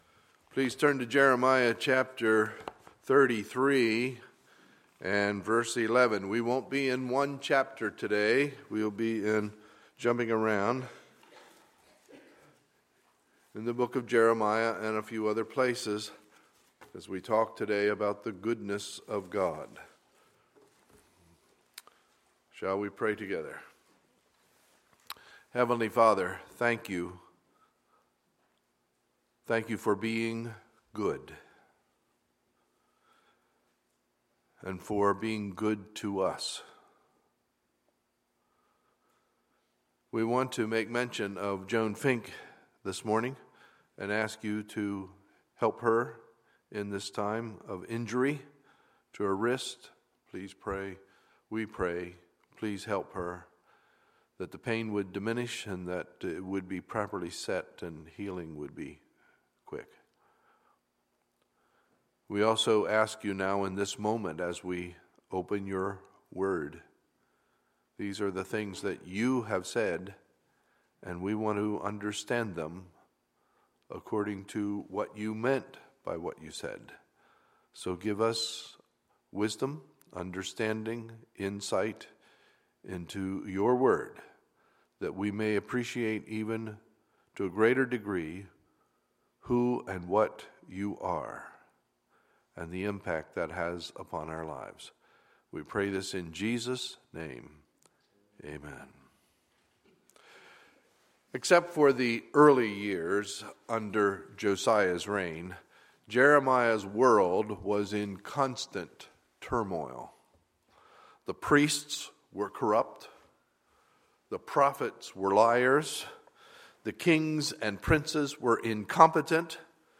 Sunday, November 22, 2015 – Sunday Morning Service